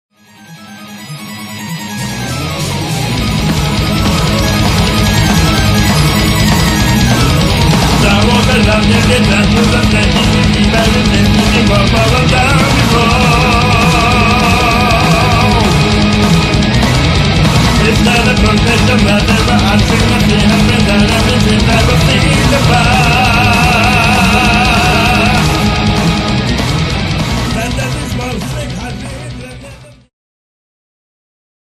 ジャンル HeavyMetal